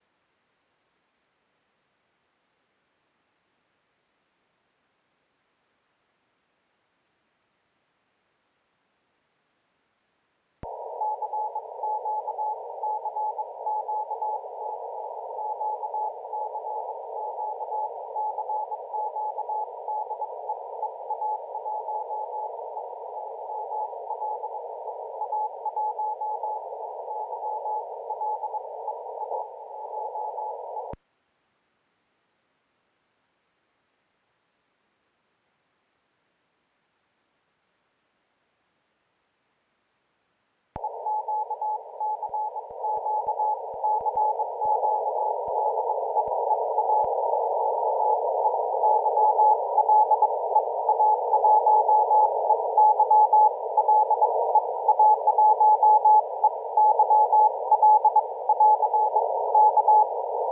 Сделал аудиозапись сигнала.
Антенна подключена, диапазон 14 МГц, RFG 12%, PRE OFF. Громкость выключена. Затем громкость установлена на единичку(первый щелчёк). В конце записи громкость плавно увеличивается до 50%.
При воспроизведении записи через наушники, слышен хисс-шум. Чем больше увеличиваем громкости, тем лучше он слышен. Это закономерно.
Это шум тракта, а не шкварчащий шум LM-ки.